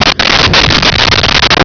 Sfx Whoosh 5102
sfx_whoosh_5102.wav